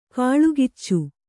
♪ kāḷugiccu